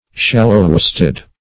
Search Result for " shallow-waisted" : The Collaborative International Dictionary of English v.0.48: Shallow-waisted \Shal"low-waist`ed\, a. (Naut.)